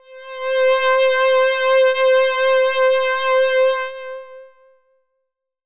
DX String C5.wav